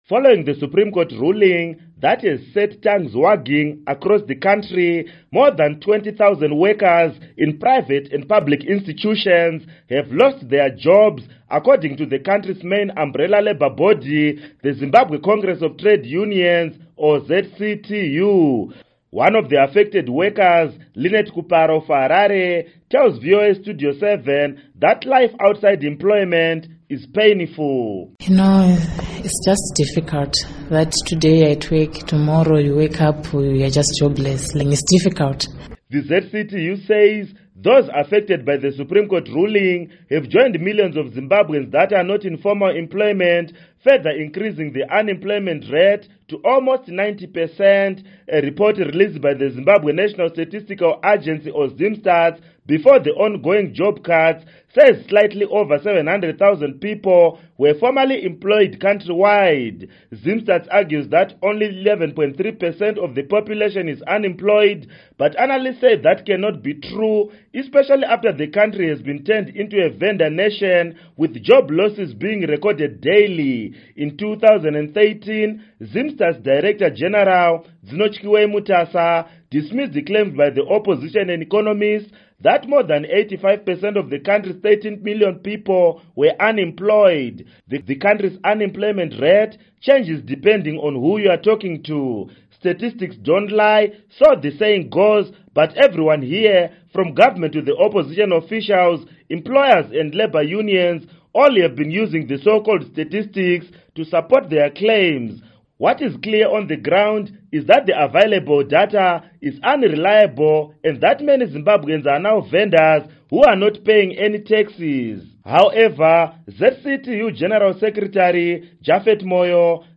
Report on Economy, Labour Law